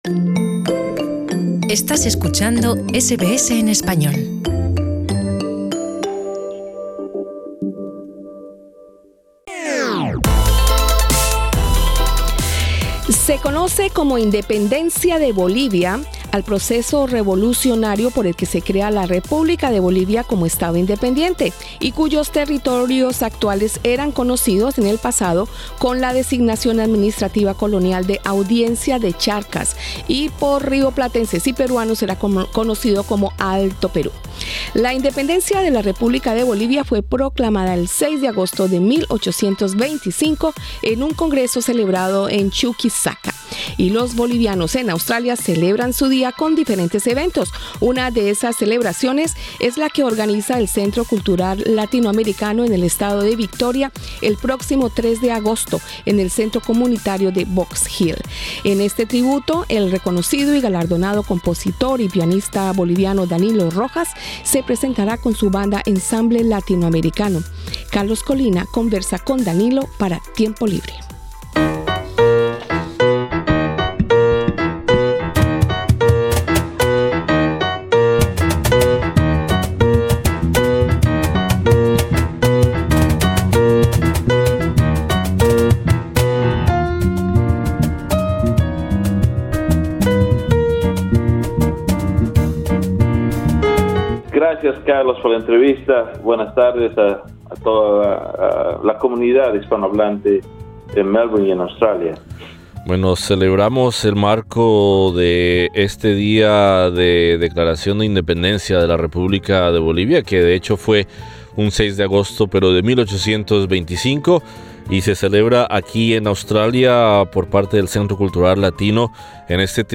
Conversamos